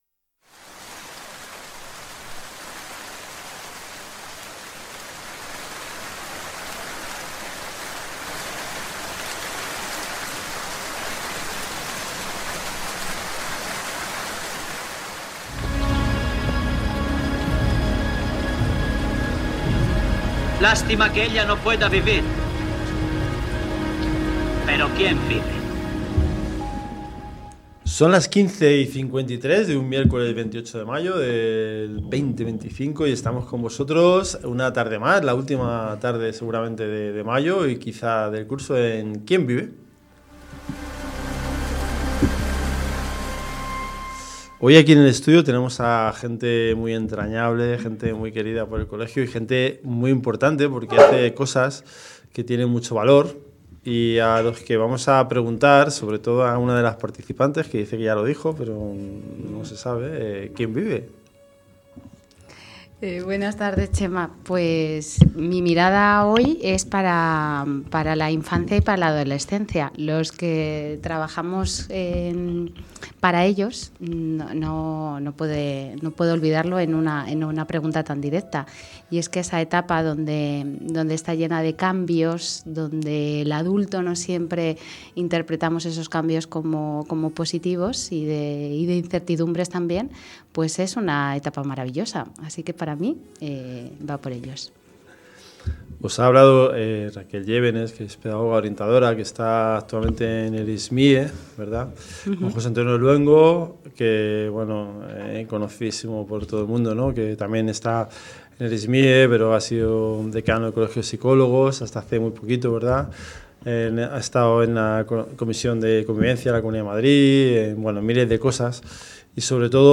Un encuentro entre educadores comprometidos con el bienestar emocional en las escuelas.